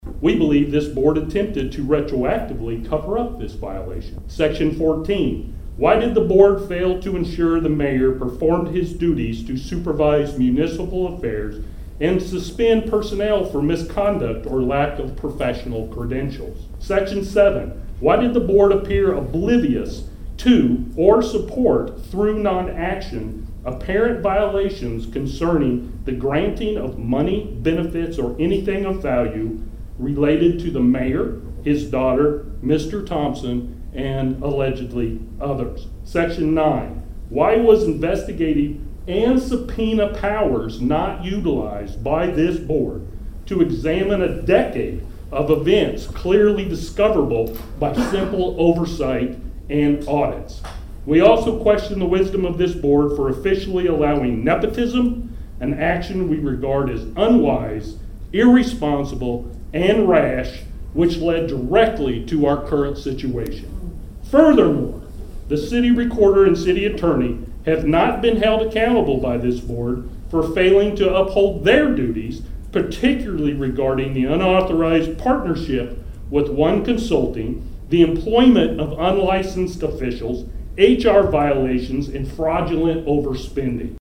Approximately 75 community members attended  a special called Martin City Board Meeting  last night at the  Martin Municipal Building where acting Mayor David Belote held an open forum to allow residents who share their concerns about City Hall.